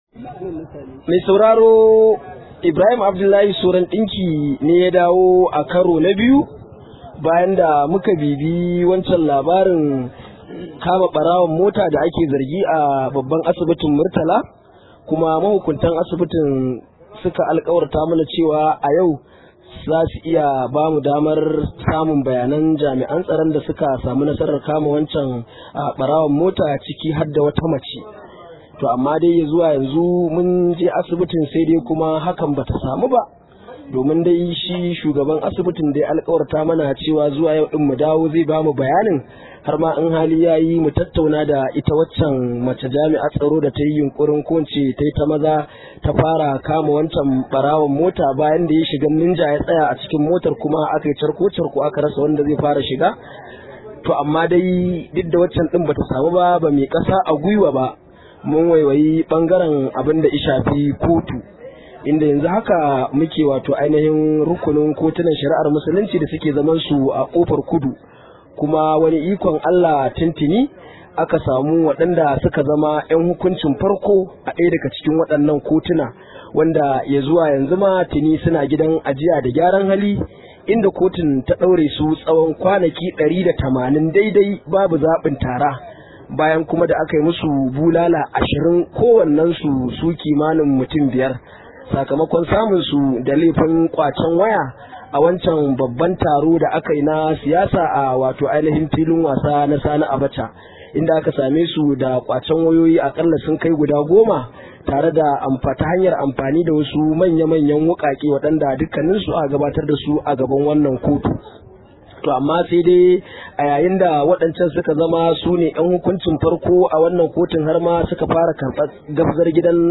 Rahoto: Ana Shari’a a kan Akuya tsawon shekaru uku ba a gama ba